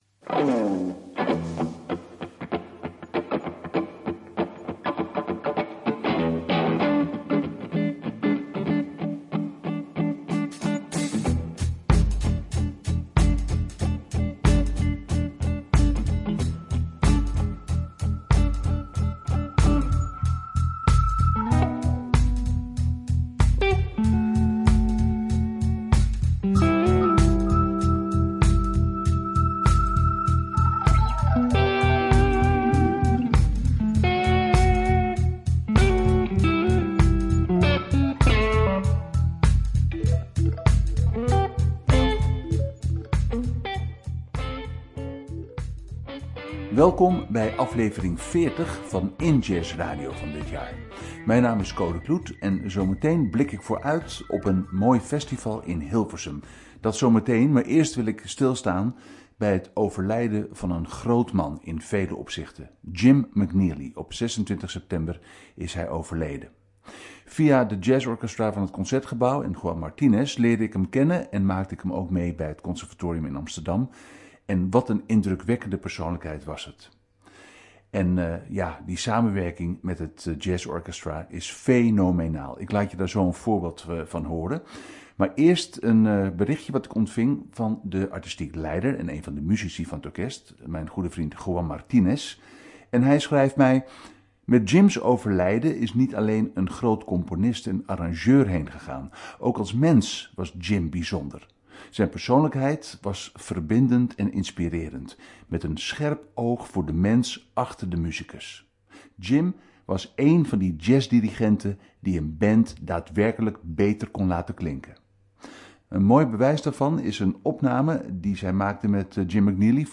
Centraal staat de promotie van jazz en beyond. Nu een preview van Hilversum Pop & Jazz.